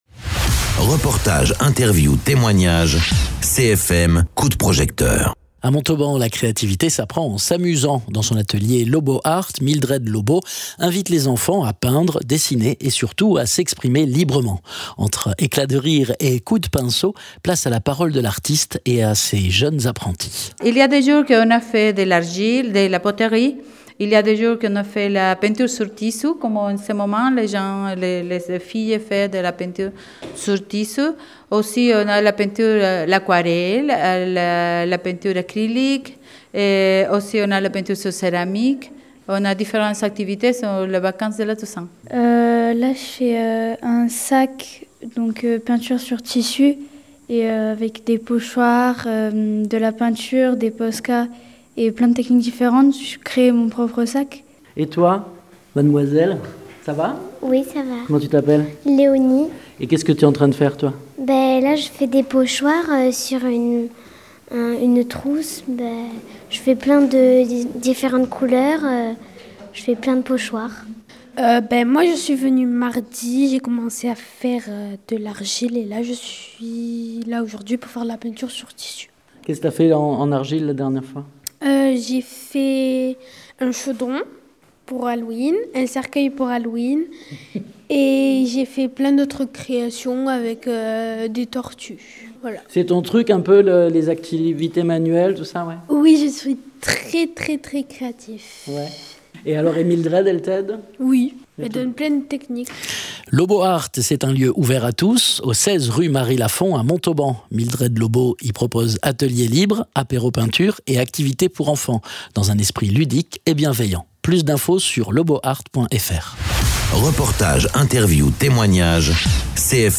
Entre éclats de rire et coups de pinceau, place à la parole de l’artiste et de ses jeunes apprentis.
Interviews